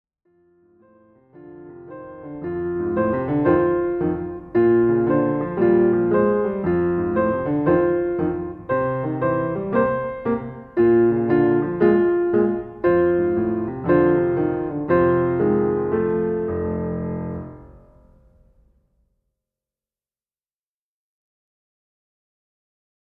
Voicing: Piano with Audio Access